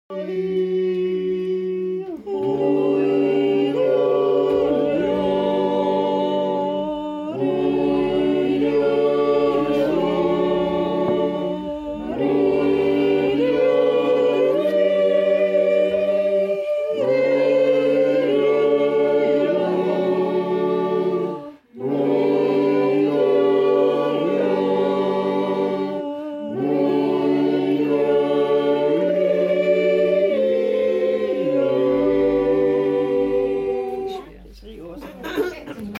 Der Roller aus dem Salzkammergut beim JodelStammtisch April 2023 gesungen